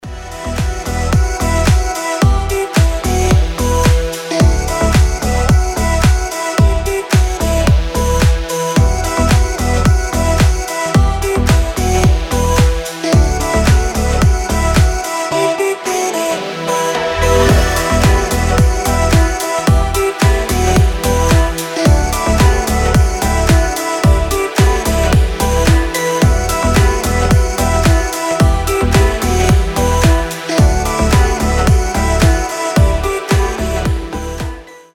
• Качество: 320, Stereo
deep house
мелодичные